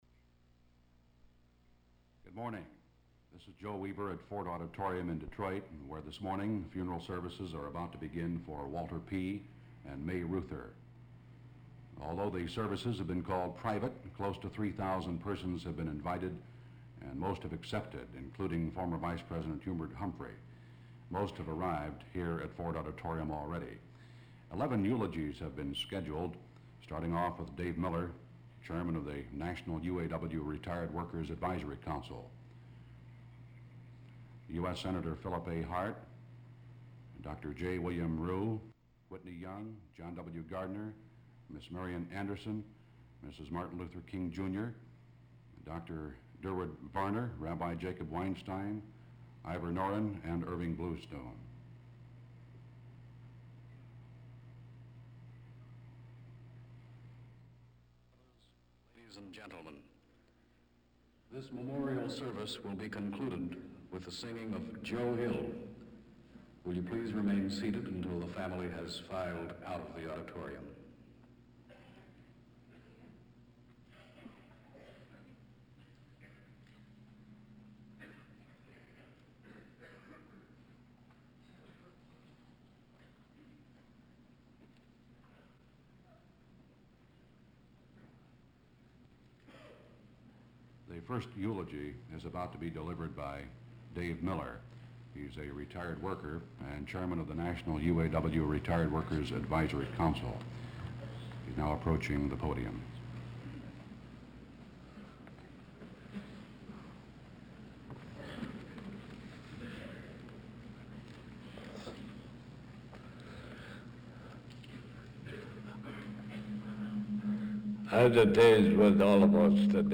Walter P. Reuther Digital Archive · Walter P. and May Reuther Memorial Service - Ford Auditorium, Reel 1 (TV audio), Detroit, MI · Omeka S Multi-Repository